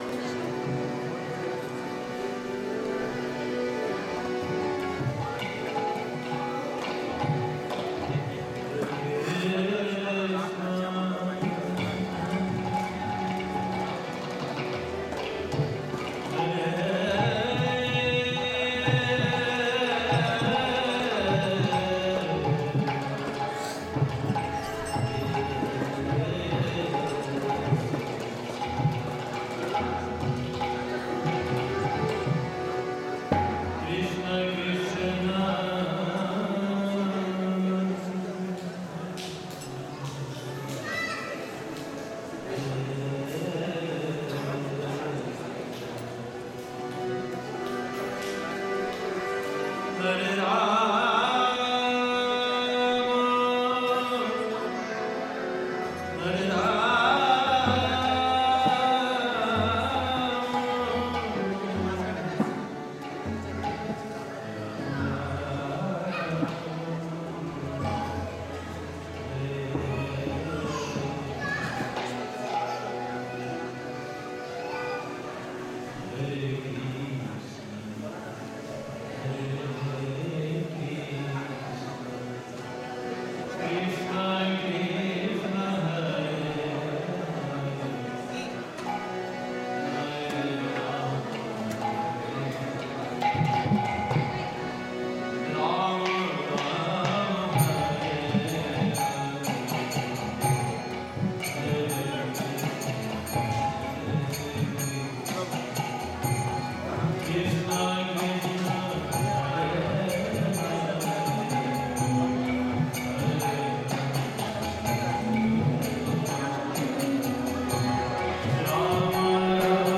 Bhajan and Lecture